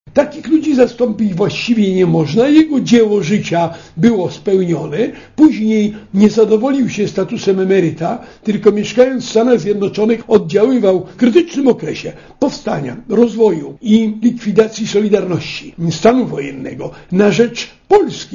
Jan Nowak-Jeziorański pozostawił po sobie testament odpowiedzialności za to, co robimy z dobrem, które mamy - wolną Polską - specjalnie dla Radia ZET postać legendarnego Kuriera z Warszawy wspominają politycy.